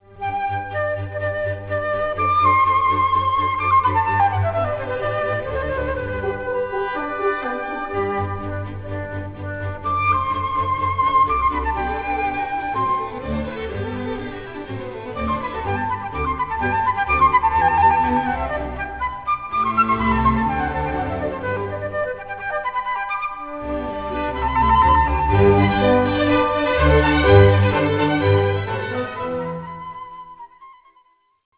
Concerto for flute and orchestra No.1 in G major